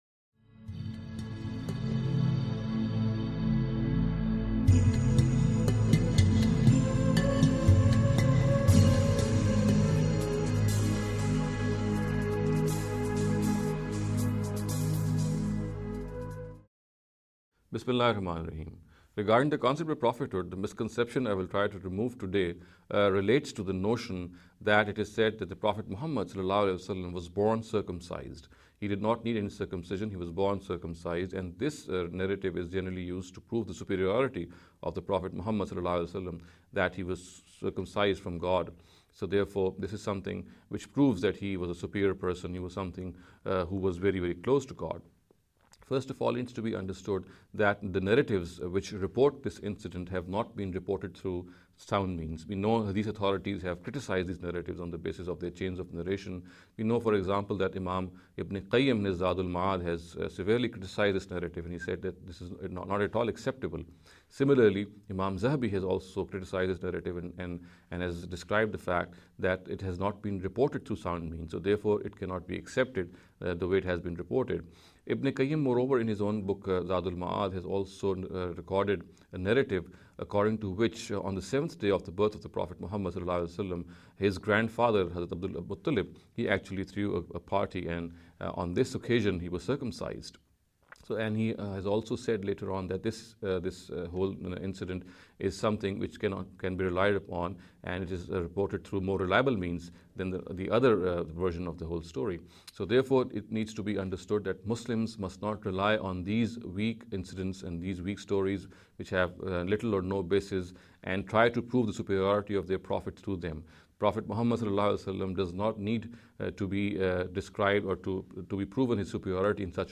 In this series of short talks